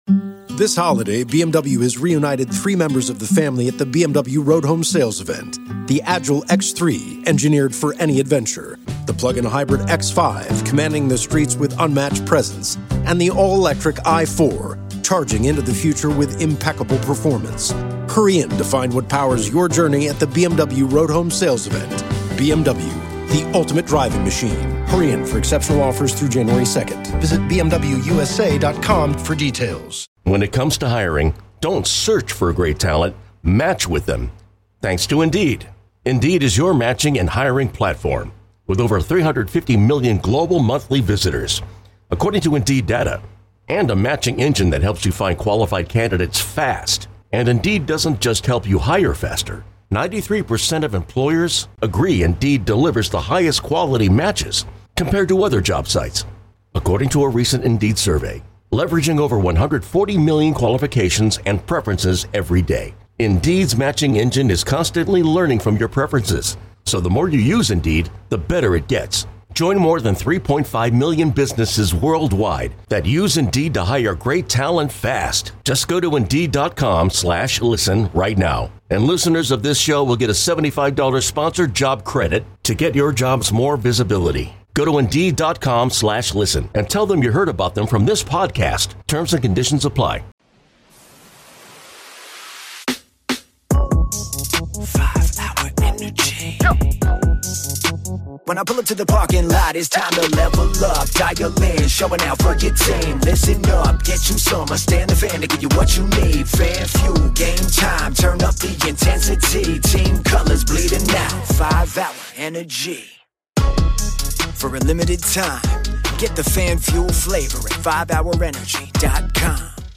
We take more phone calls.